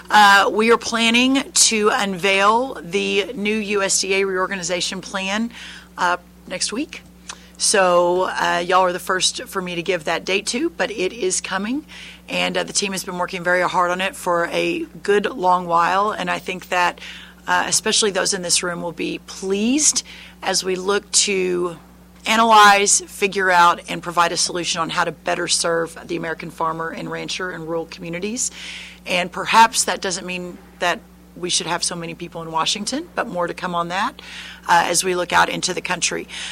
Speaking to Farm broadcasters this week, Rollins said there would be a USDA re-organization announcement next week, hinting at moving employees out of Washington to regional offices around the country,